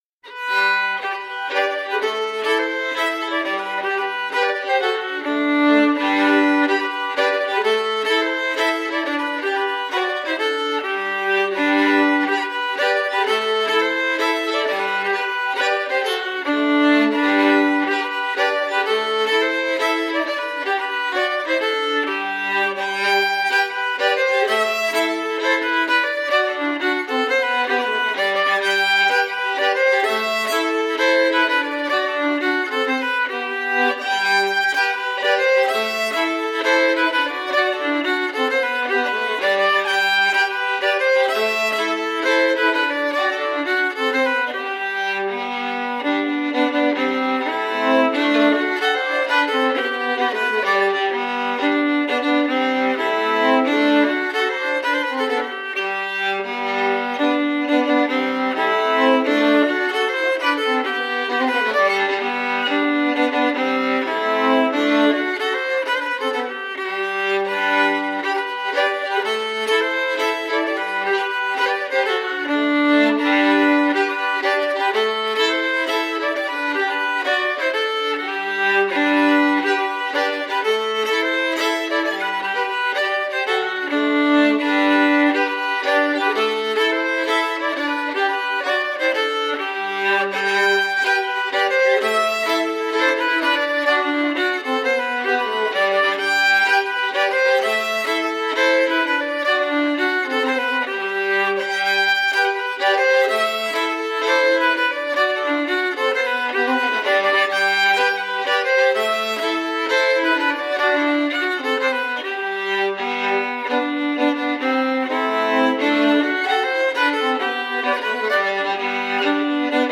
Andra allspelslåtar
G dur låtar